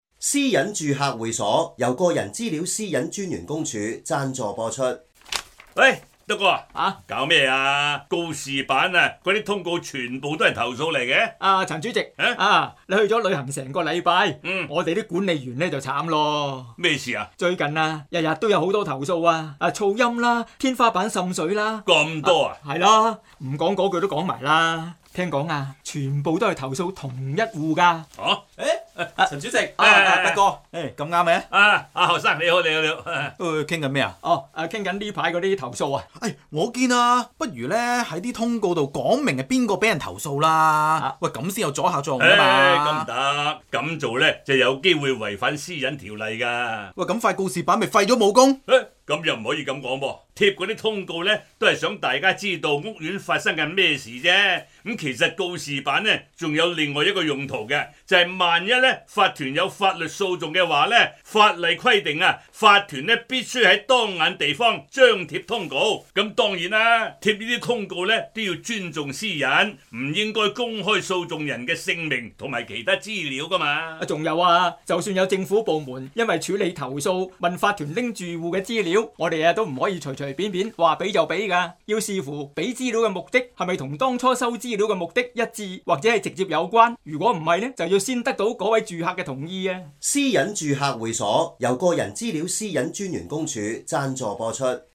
广播剧《私隐住客会所》(2019年5月)